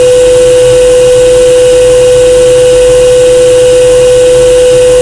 騒音の中での聞きとりやすさを比べました。
人の声域の代表として「500ヘルツの単音」と、
騒音の代表としてホワイトノイズを用い、
模型箱(50cm立方体)内の音を比較しました。
単音の明瞭度が低く、
聞き取りにくくなります。
PB_WN_500.wav